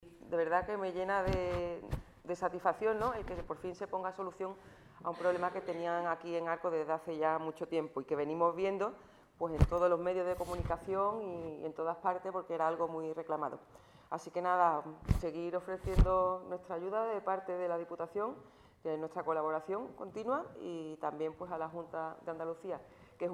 Estos acuerdos han sido presentados a los medios de comunicación en una rueda de prensa.